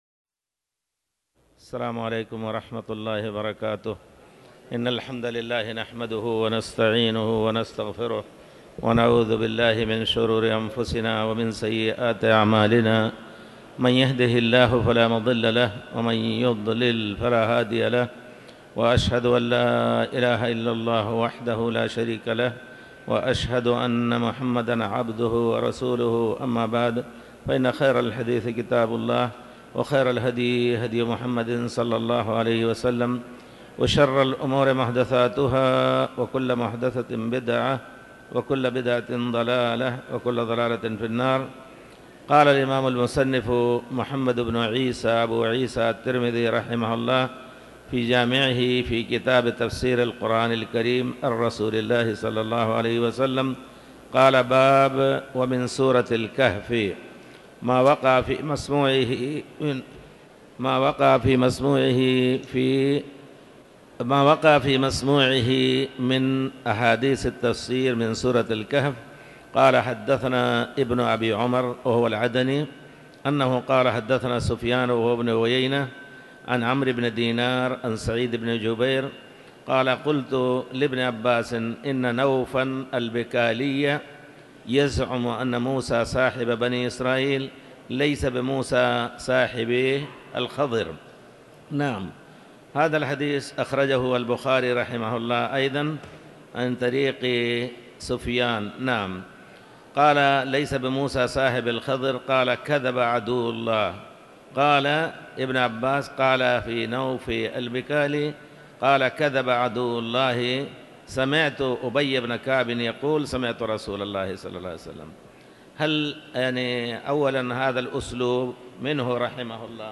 تاريخ النشر ٢ ربيع الأول ١٤٤٠ هـ المكان: المسجد الحرام الشيخ